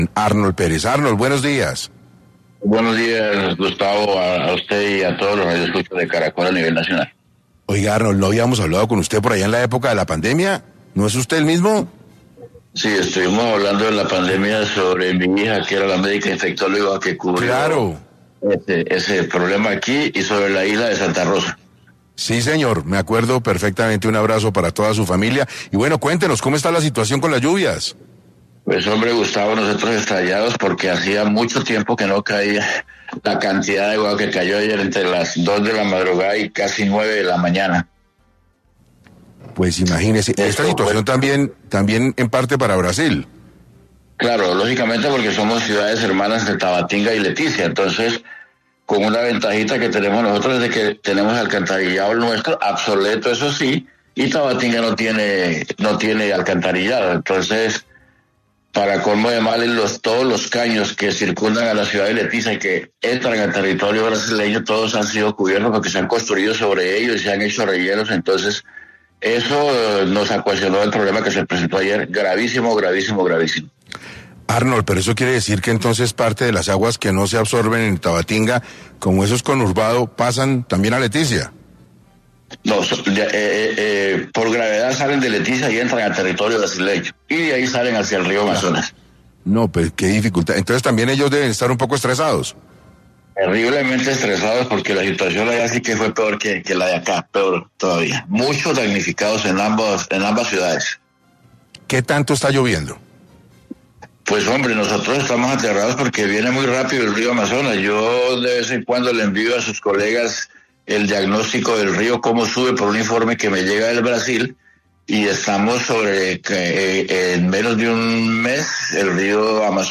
“Hay muchos damnificados, tanto en Colombia como en Brasil”: Habitante de Leticia sobre inundaciones